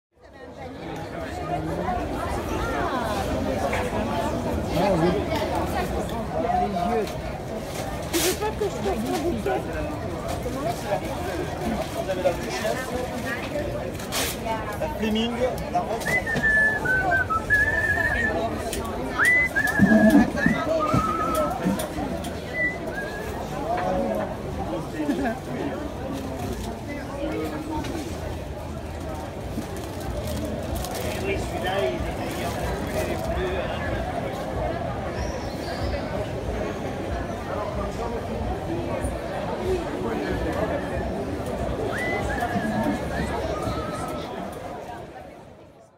На этой странице собраны разнообразные звуки рынка: гул толпы, крики продавцов, стук товаров, смех покупателей.
Голоса людей на рынке, короткие живые звуки